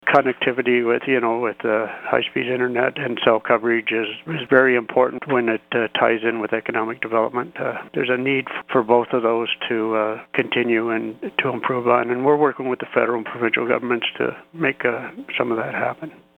As we continue our look back at 2019, we chatted with the Warden of the County Rick Phillips.